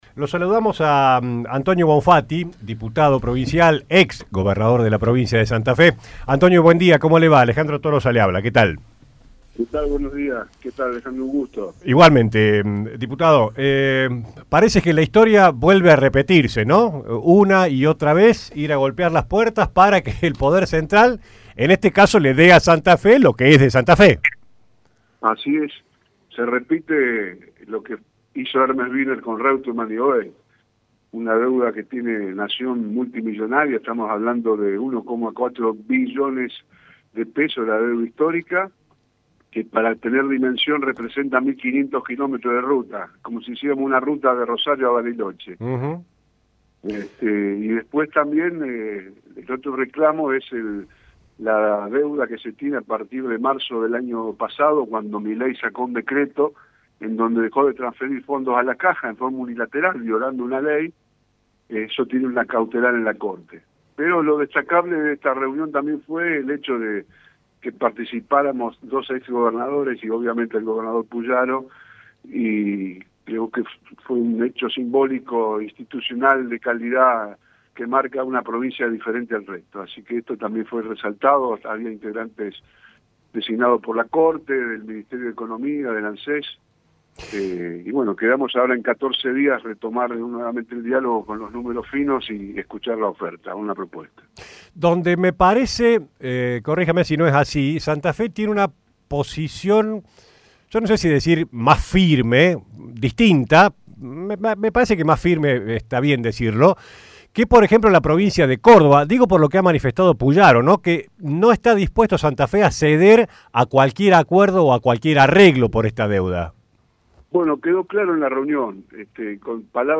El ex Gobernador de Santa Fe y actual diputado provincial, Antonio Bonfatti, habló en el programa La Barra de Casal y se refirió a la reunión que mantuvieron junto al otro ex Gobernador Omar Perotti y al actual Maximiliano Pullaro en la Corte Suprema por la deuda previsional que mantiene Nación con la Provincia.